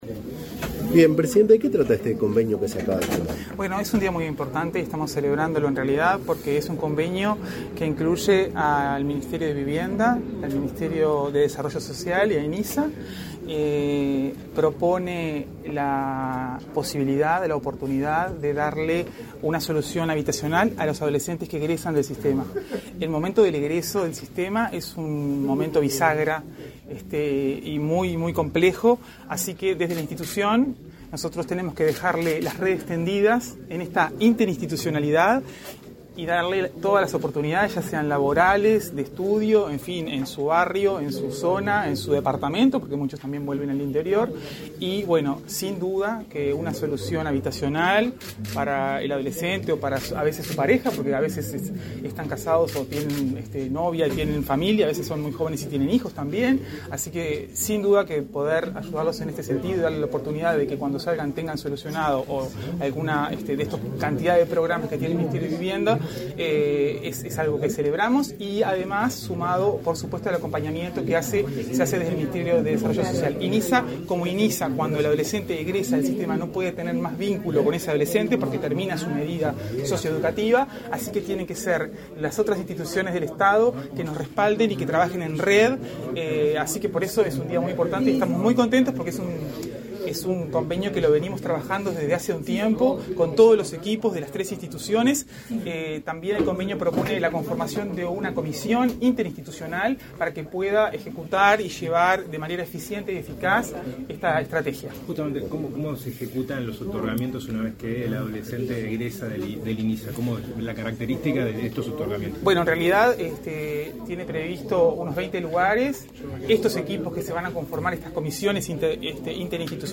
Declaraciones a la prensa de la presidenta del Inisa, Rossana de Olivera